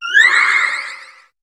Cri de Cupcanaille dans Pokémon HOME.